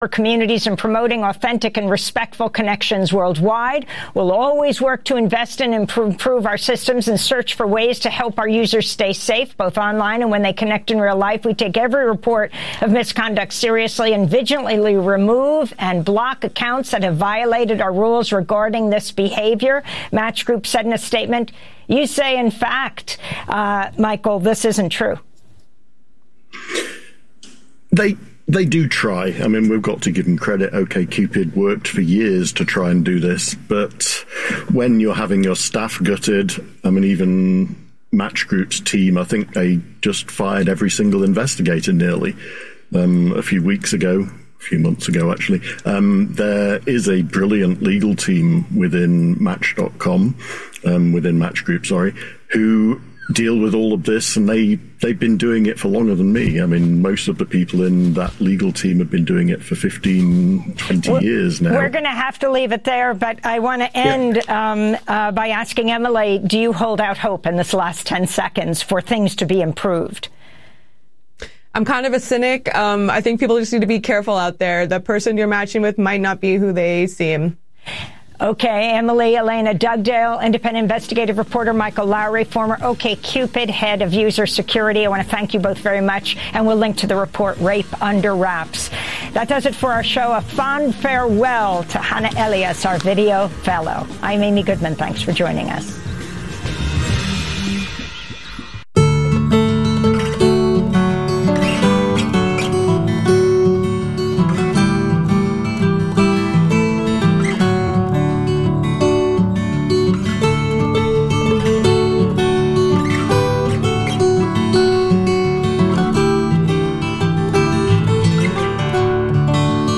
Evening News on 02/14/25
Non-corporate, community-powered, local, national and international news